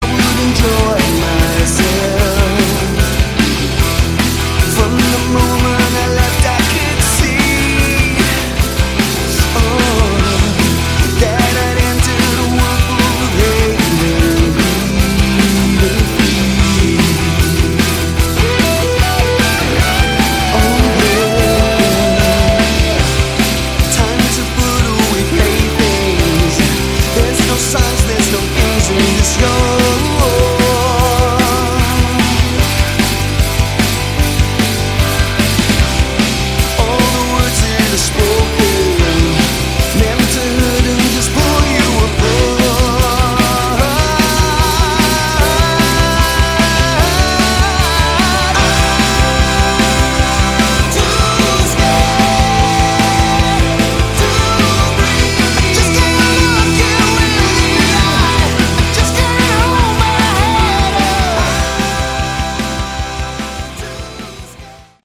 Category: Hard Rock
guitars
vocals
drums
keyboards